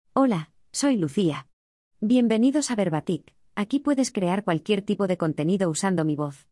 LuciaFemale Castilian Spanish AI voice
Lucia is a female AI voice for Castilian Spanish.
Voice: LuciaGender: FemaleLanguage: Castilian SpanishID: lucia-es-es
Voice sample
Listen to Lucia's female Castilian Spanish voice.
Lucia delivers clear pronunciation with authentic Castilian Spanish intonation, making your content sound professionally produced.